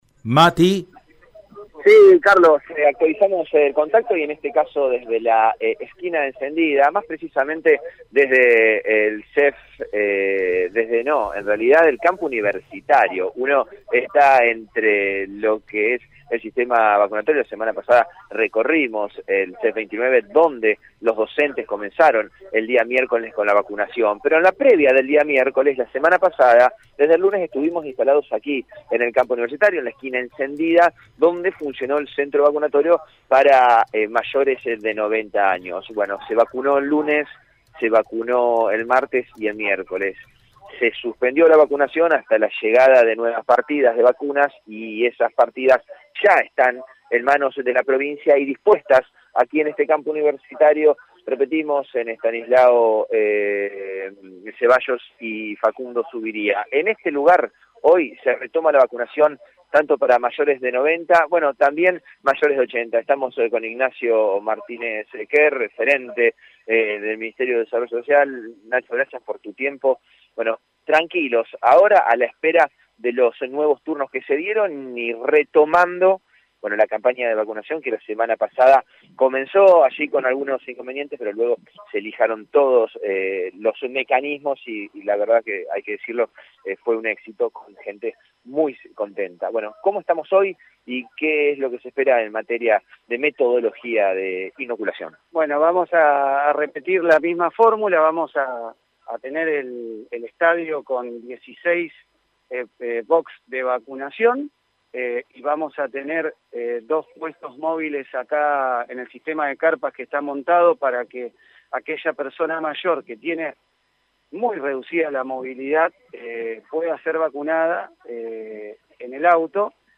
En dialogo con el móvil Radio EME, Ignacio Martínez Kerz,  secretario de Prácticas Sociocomunitarias de la provincia, comentó el mecanismo que llevaran adelante en la reanudación de la inmunización a adultos mayores: «Vamos a repetir la misma formula que la semana pasada.